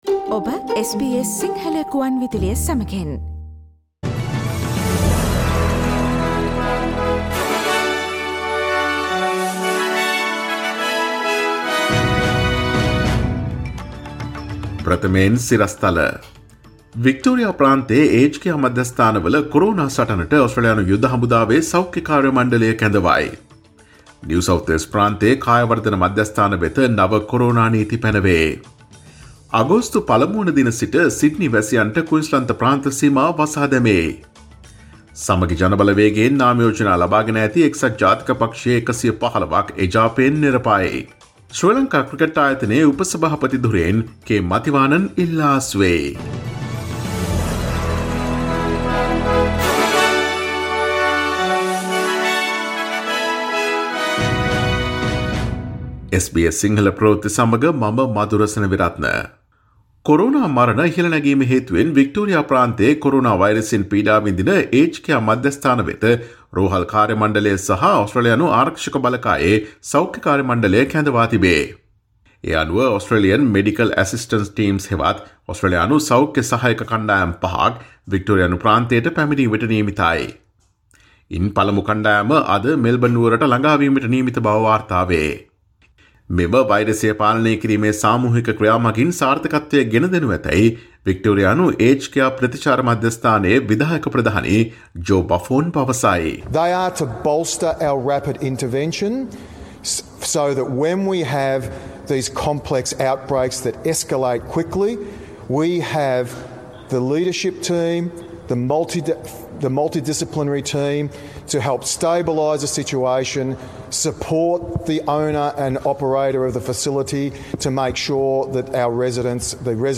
Daily News bulletin of SBS Sinhala Service: Thursday 30 July 2020